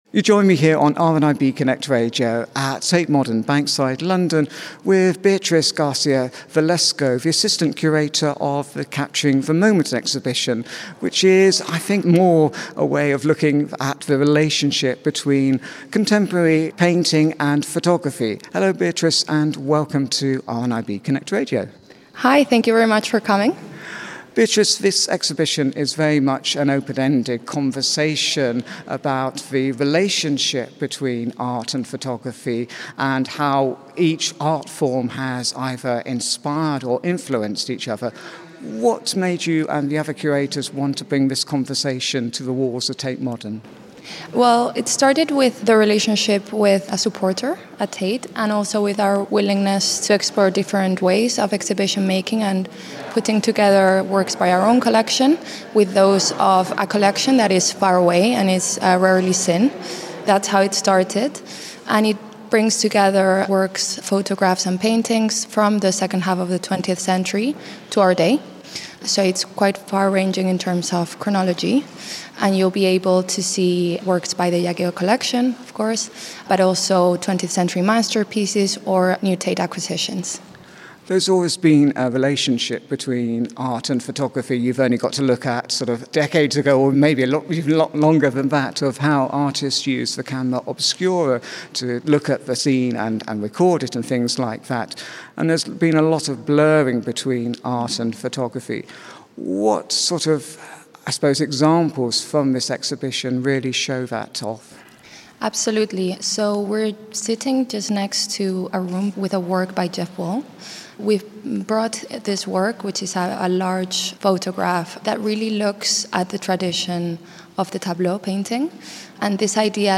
in the gallery spaces at Tate Modern